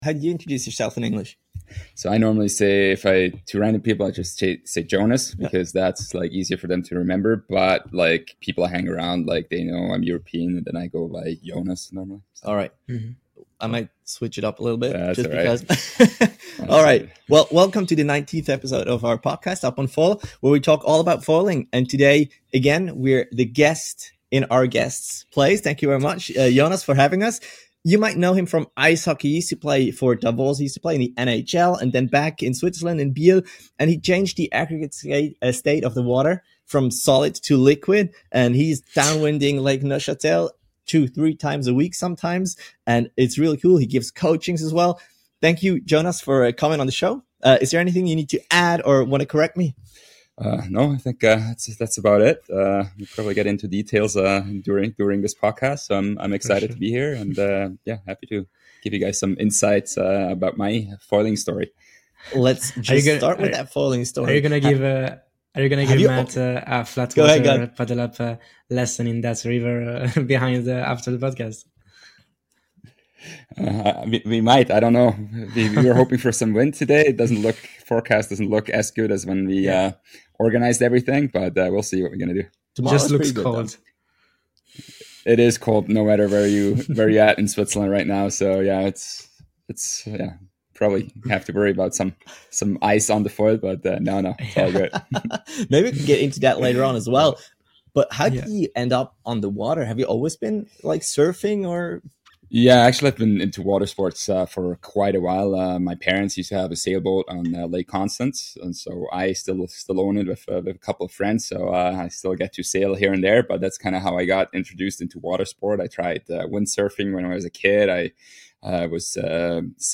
2 fellow foilers - as they chat about foiling with more or less famous foilers about being on foil.